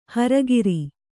♪ hara giri